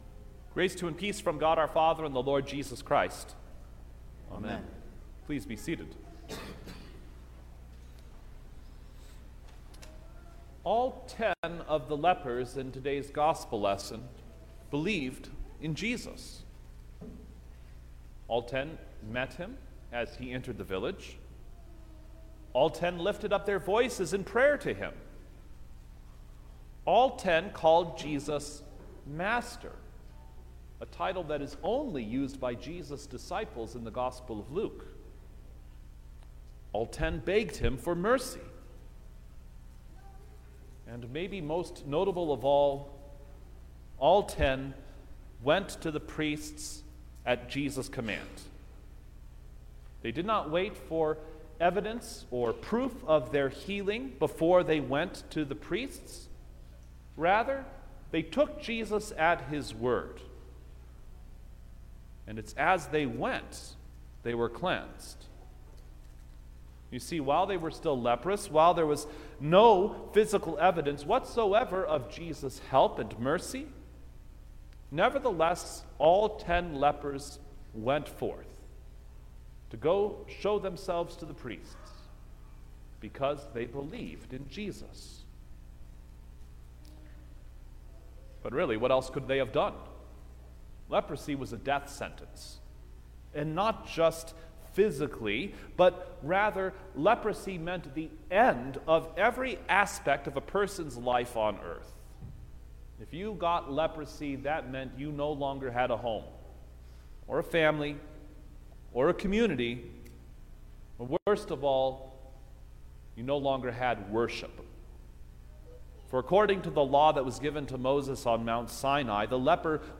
September-5_2021_Fourteenth-Sunday-after-Trinity_sermon-stereo.mp3